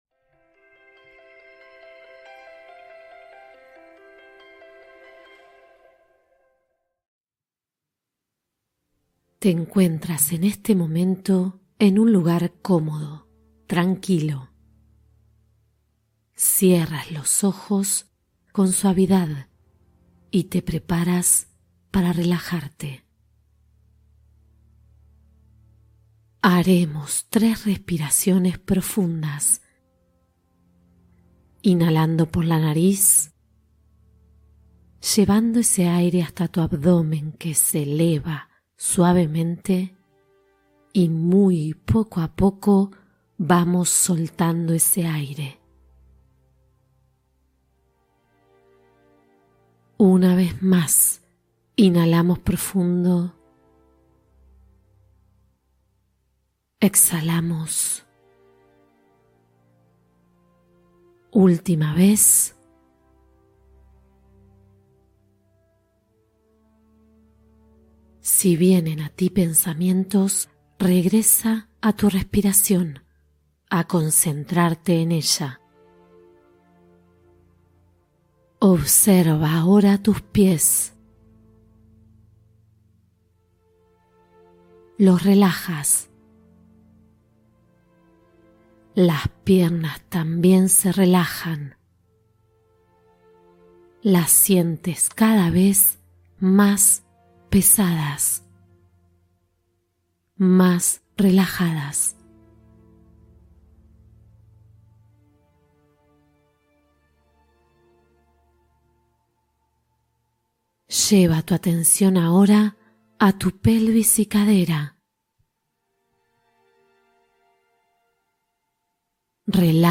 Fortalece tu autoestima con mindfulness: meditación guiada para la confianza